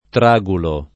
tragulo